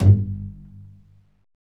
Index of /90_sSampleCDs/Roland LCDP13 String Sections/STR_Cbs FX/STR_Cbs Pizz